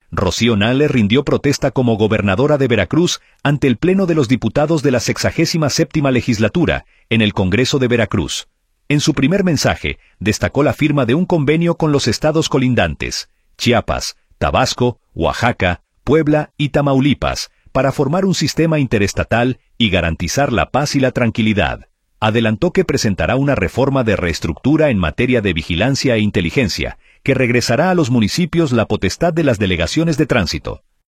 Rocío Nahle rindió protesta como gobernadora de Veracruz ante el pleno de los diputados de la 67 Legislatura, en el Congreso de Veracruz.